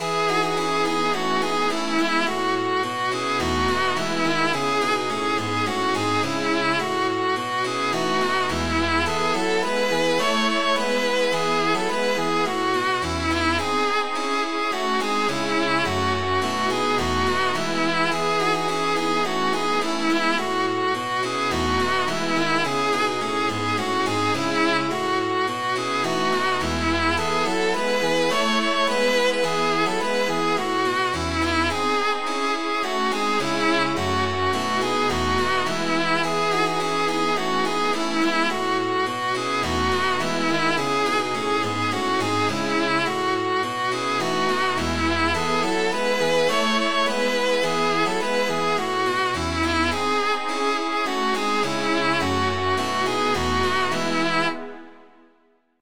Midi File, Lyrics and Information to Once I Loved A Maiden Fair